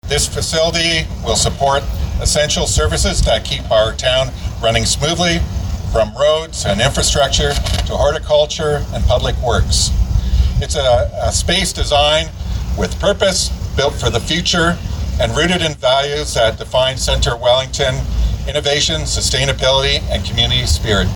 Speaking at the groundbreaking, Mayor Shawn Watters says the operations centre represents more than just bricks and mortar. He says it’s a symbol of the township’s commitment to building a thriving, resilient, and forward-thinking municipality.